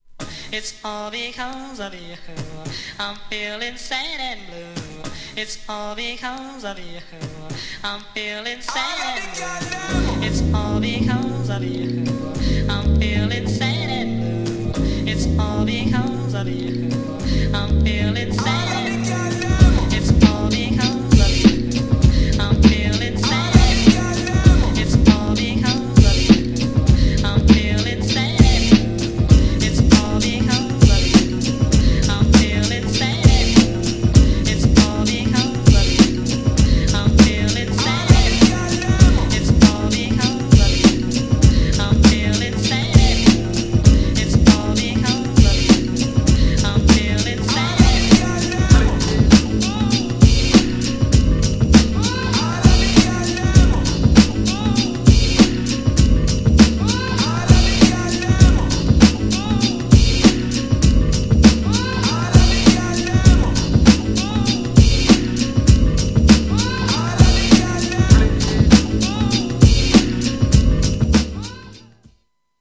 GENRE Hip Hop
BPM 96〜100BPM
# ファンキーなHIPHOP # 打ち付けるビートがカッコイイ
# 重厚なビートにトバされる!